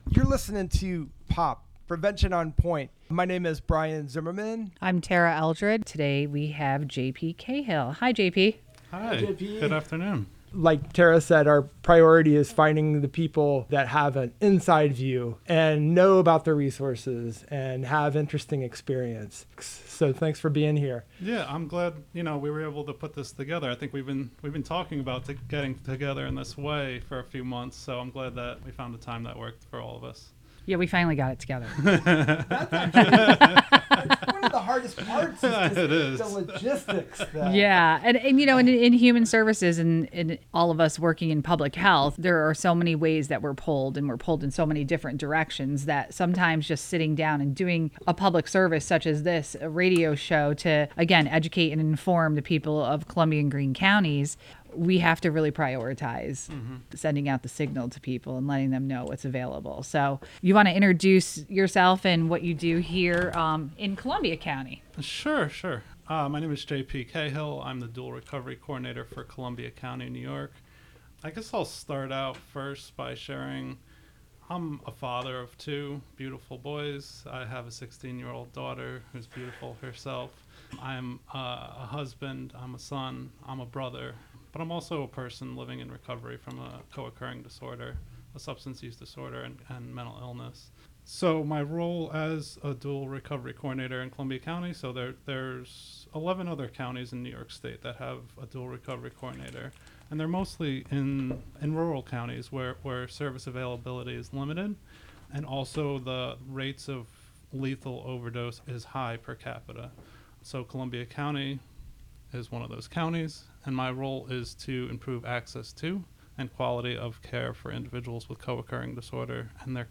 Lively conversation and useful information about substance use and misuse, prevention, wellness, and community.